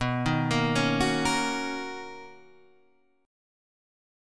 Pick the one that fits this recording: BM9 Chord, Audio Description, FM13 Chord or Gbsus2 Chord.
BM9 Chord